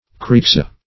Corixa - definition of Corixa - synonyms, pronunciation, spelling from Free Dictionary